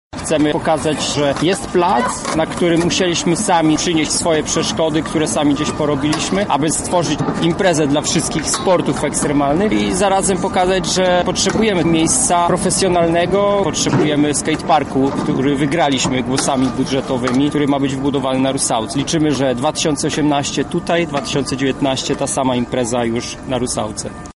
propagator jazdy na deskorolce